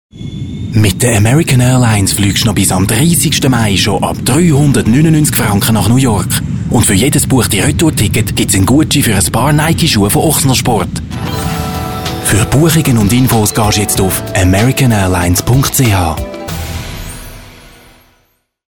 Native speaker Male 30-50 lat
Warm, precise voice appropriate for ages 25-45.
Nagranie lektorskie